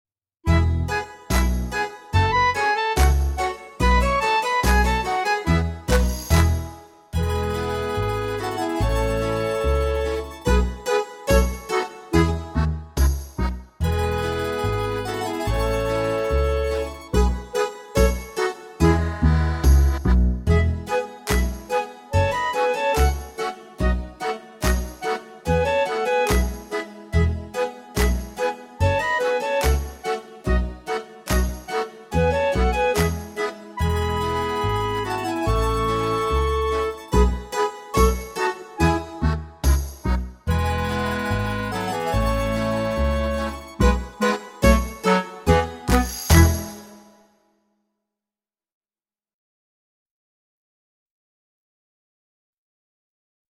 VS Nobody Else but Me (backing track)